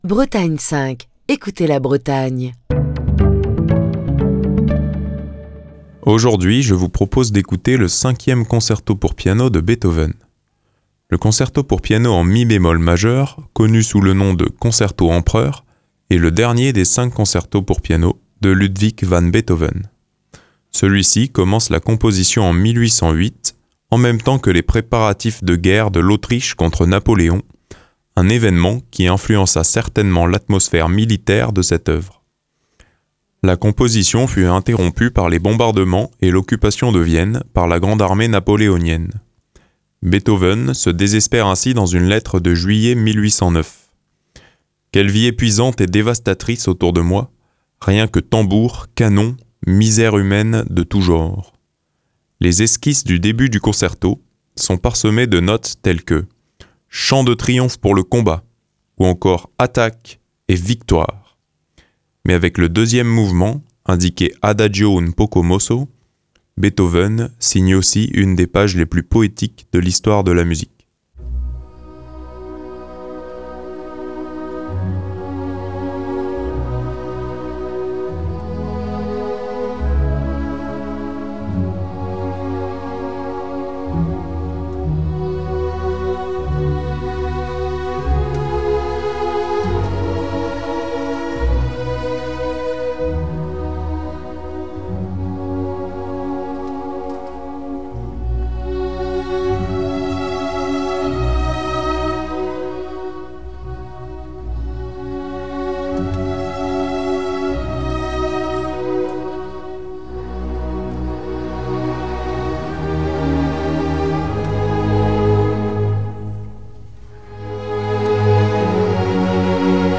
Le Concerto pour piano en mi bémol majeur connu sous le nom de Concerto Empereur, est le dernier des cinq concertos pour piano de Ludwig van Beethoven.